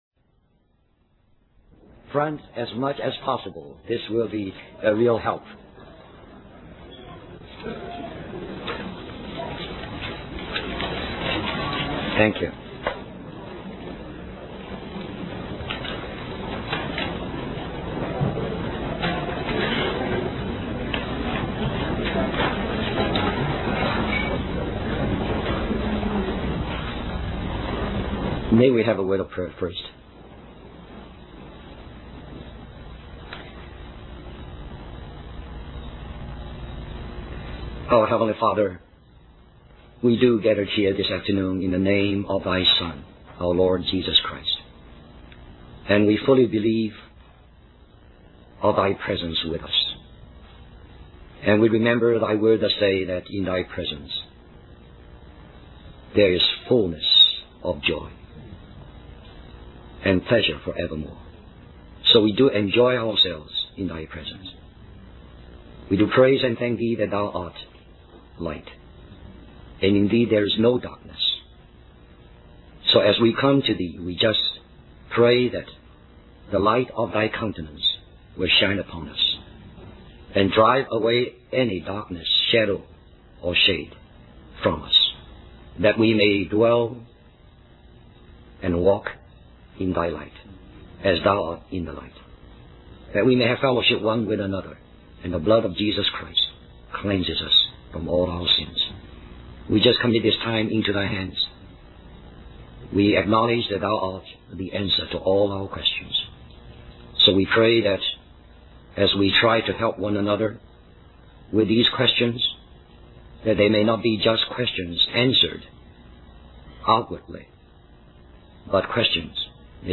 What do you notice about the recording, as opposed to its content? Christian Family Conference